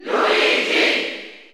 File:Luigi Cheer German SSBU.ogg
Category: Crowd cheers (SSBU) You cannot overwrite this file.
Luigi_Cheer_German_SSBU.ogg.mp3